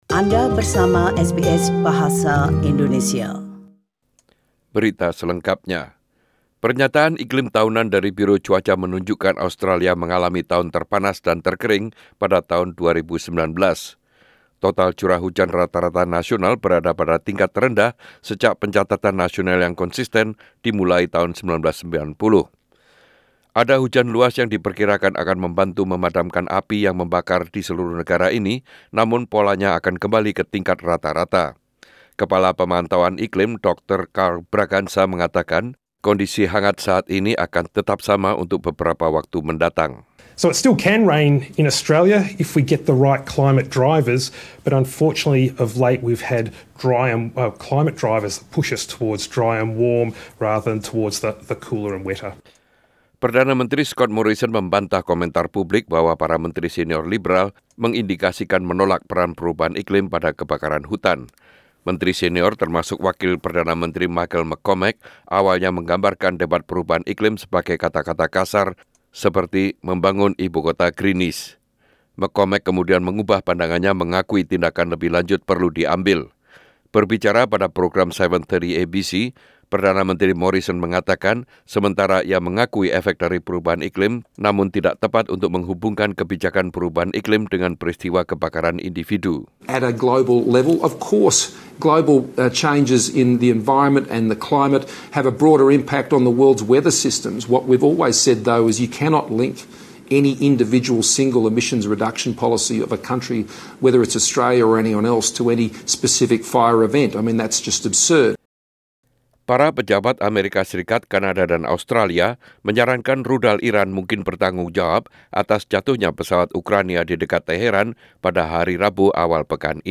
SBS Radio News in Indonesian - 10/01/2020